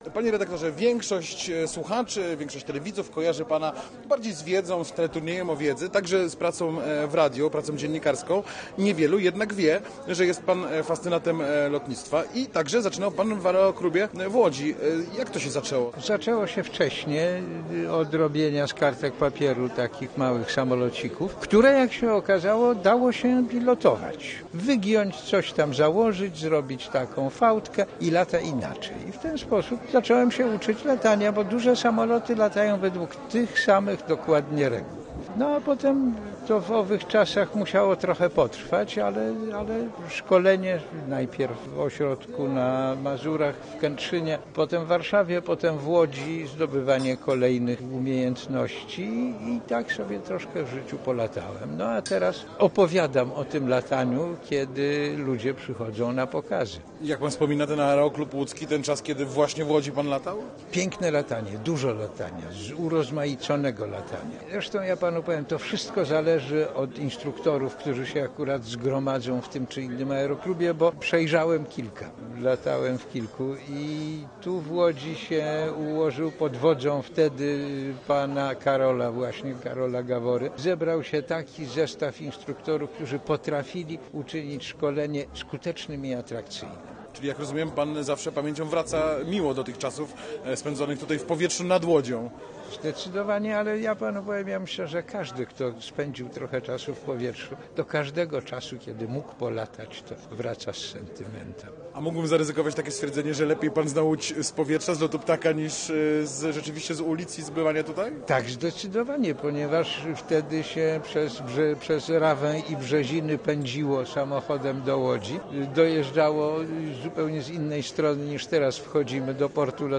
Posłuchaj rozmowy: Nazwa Plik Autor Z Tadeuszem Sznukiem audio (m4a) audio (oga) Tadeusz Sznuk razem z nagrodą Złotego Mikrofonu otrzymał również honorowe członkostwo Klubu Seniora Lotnictwa.